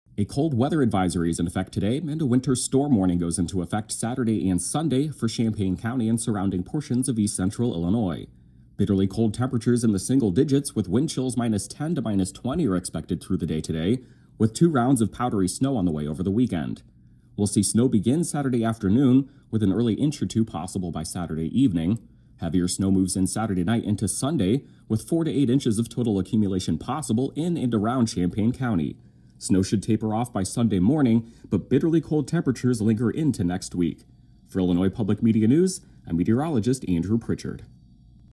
Weather forecast